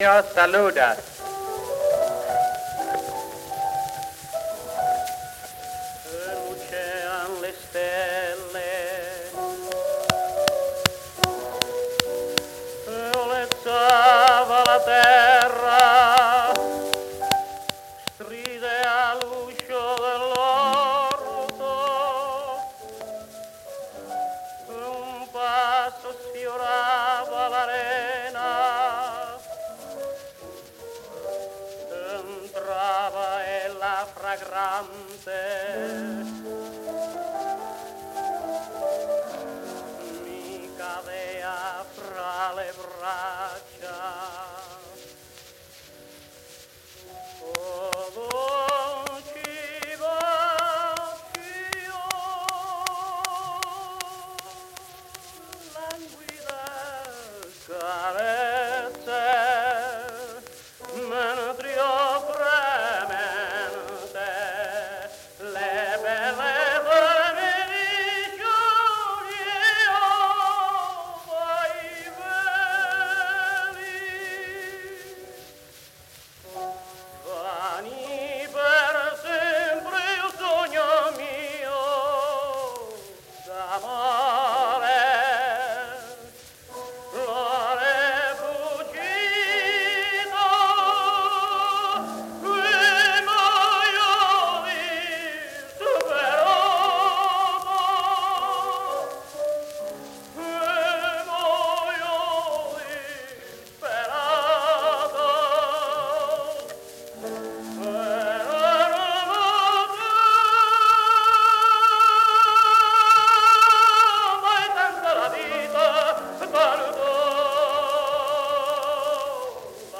cylinder recording